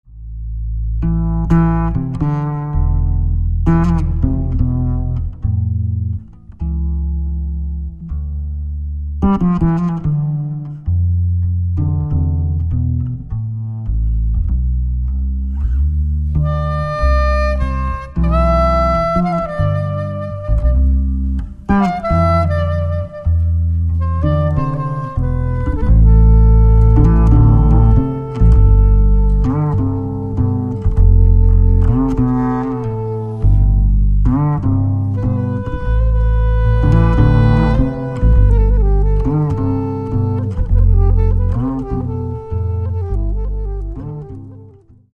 Catalogue -> Jazz & almost -> Collections, Jams, Live
acoustic bass
alto sax
drums
music: ukrainian folk song